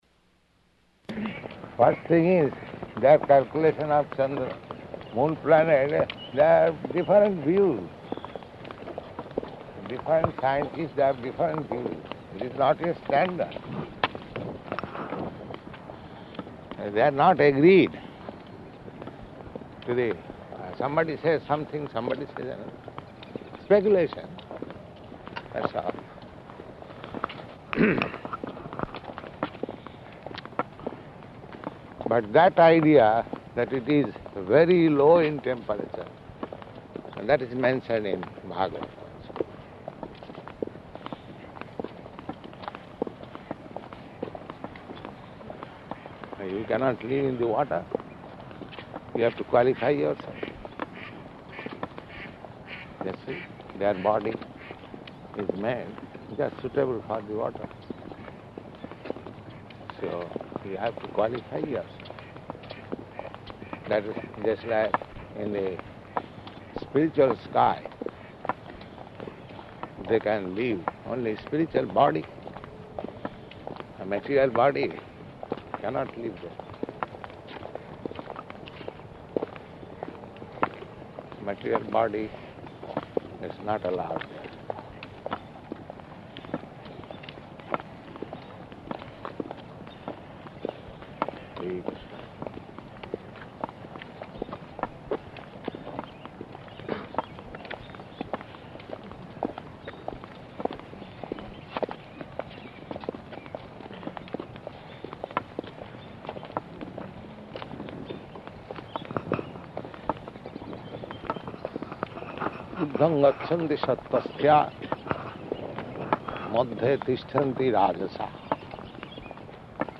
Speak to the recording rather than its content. Morning Walk at Stow Lake Location: San Francisco